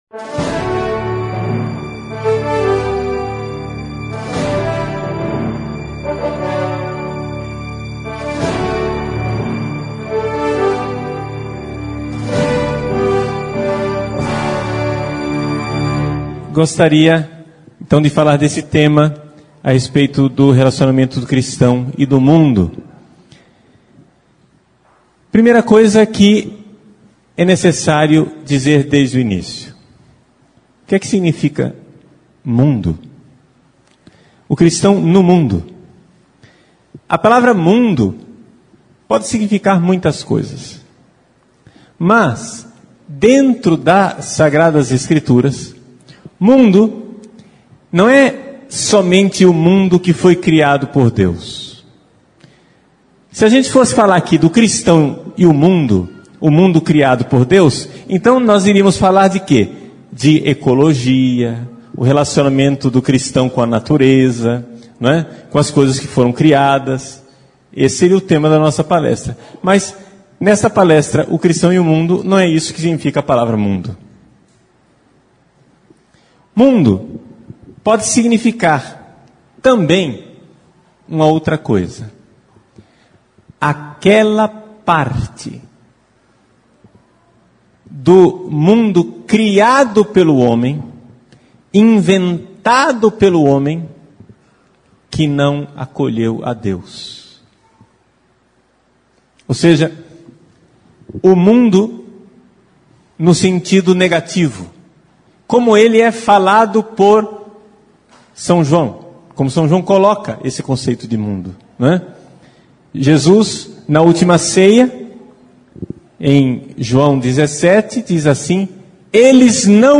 Esta pregação é uma denúncia. Não é de hoje que nossos católicos têm abdicado a virtude da fortaleza para abraçar, não a paz que Cristo nos dá, mas um "pacifismo" ideológico e absolutamente anticristão.